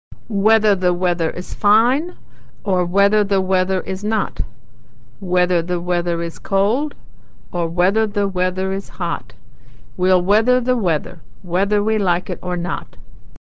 Consonant Sound /w/ - Pactice - Whether, Weather - Authentic American Pronunciation
Consonant Sound Voiced /w/